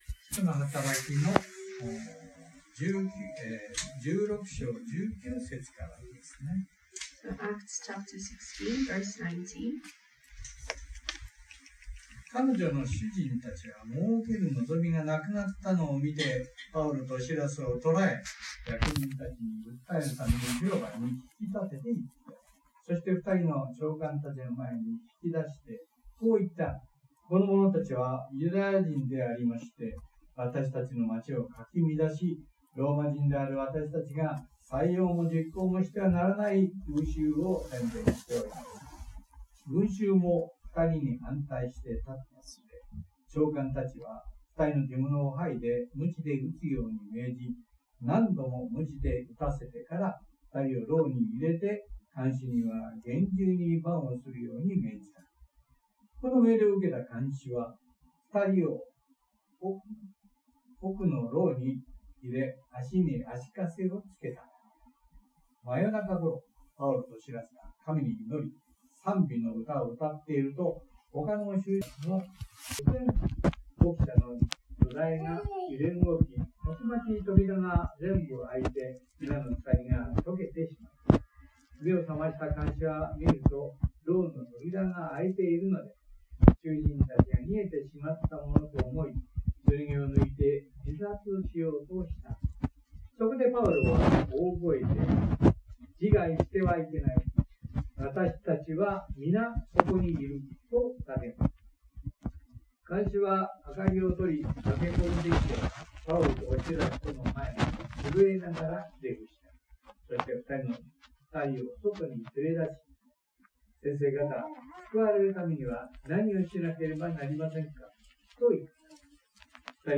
(Sunday worship recording)